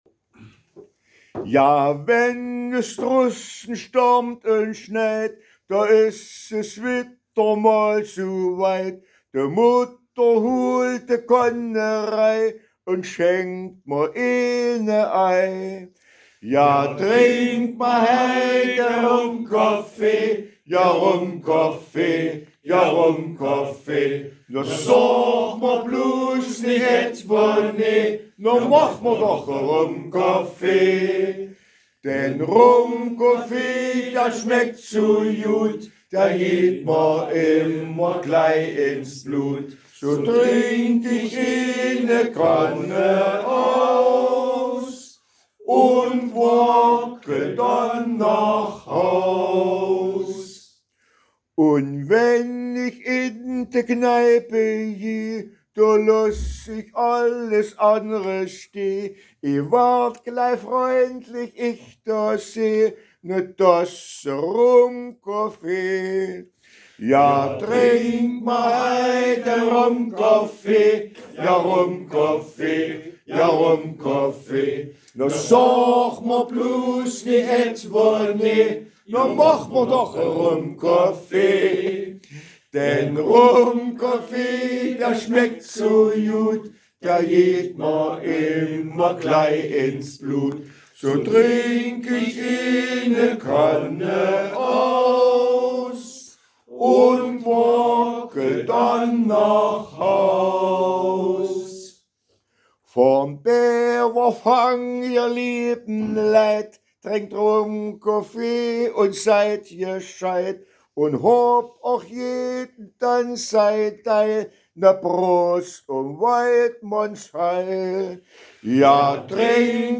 Lied:
Tautenhainer Männerchor “Hilaritas singt: